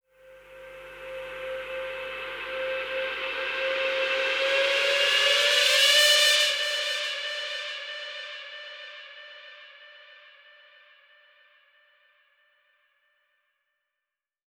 sci fi (raiser)
Вложения VTS1 25 Kit 140BPM Racer FX.wav VTS1 25 Kit 140BPM Racer FX.wav 2,4 MB · Просмотры: 211